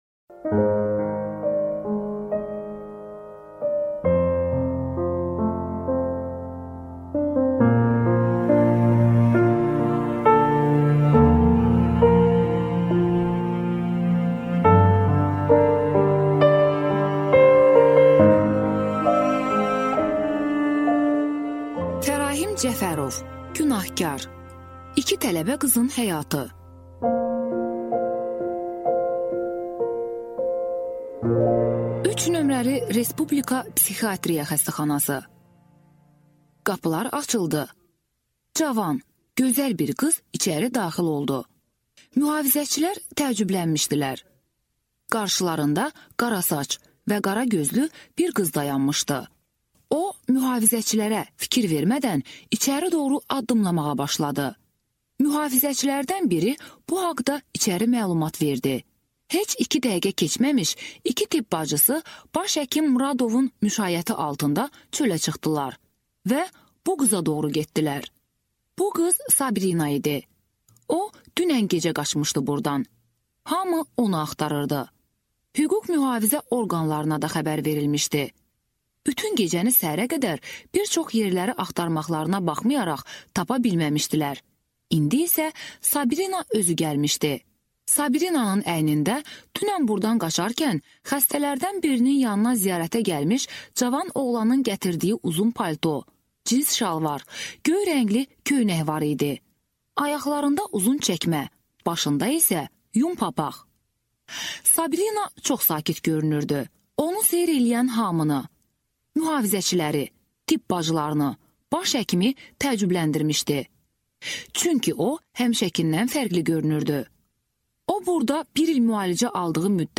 Аудиокнига Günahkar | Библиотека аудиокниг